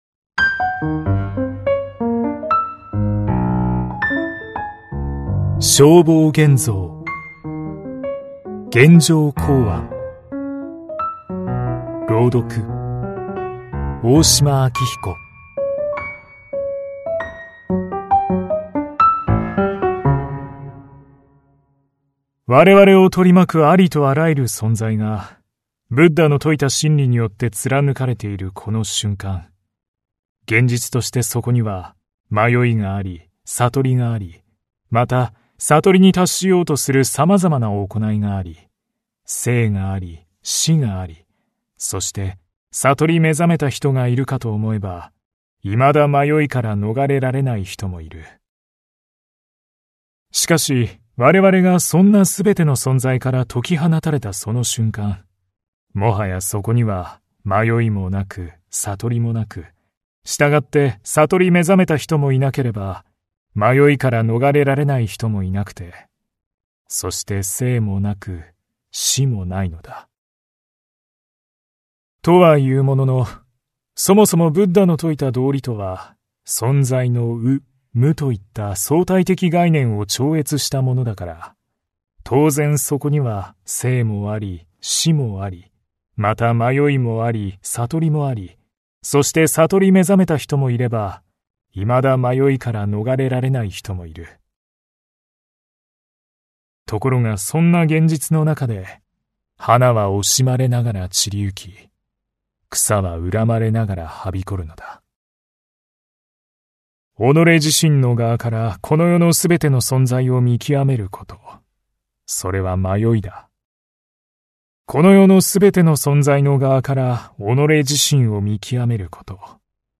[オーディオブック] 現代語訳 正法眼蔵 現成公案